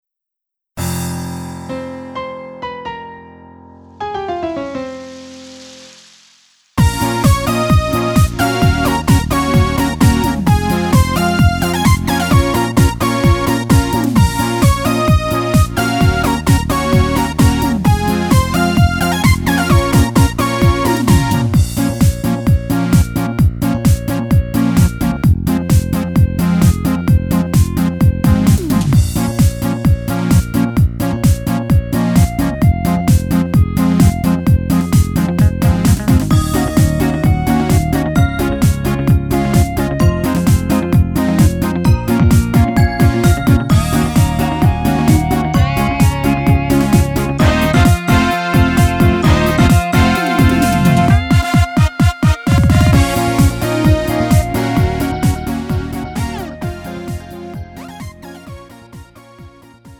음정 -1키 3:15
장르 가요 구분 Lite MR
Lite MR은 저렴한 가격에 간단한 연습이나 취미용으로 활용할 수 있는 가벼운 반주입니다.